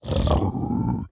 Minecraft Version Minecraft Version 1.21.5 Latest Release | Latest Snapshot 1.21.5 / assets / minecraft / sounds / mob / piglin_brute / idle7.ogg Compare With Compare With Latest Release | Latest Snapshot